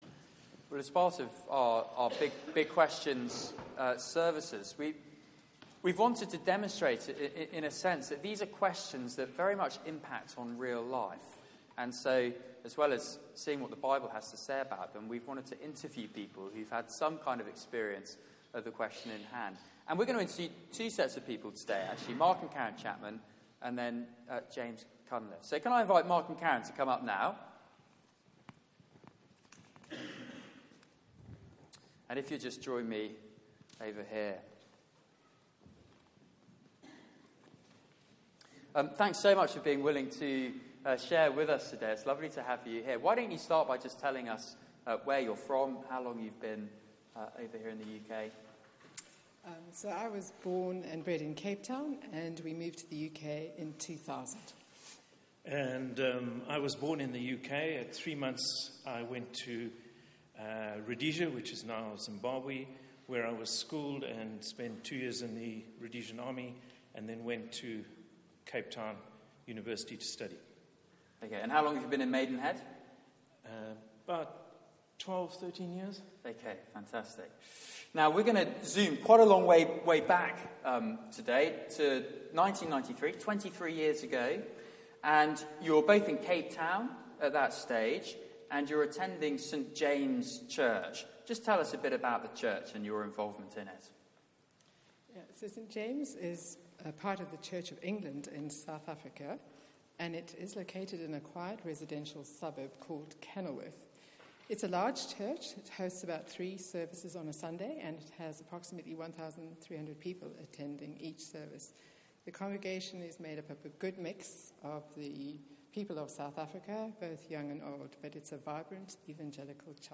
Media for 6:30pm Service on Sun 20th Mar 2016 18:30 Speaker
Sermon